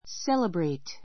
séləbreit